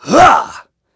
Clause_ice-Vox_Attack1.wav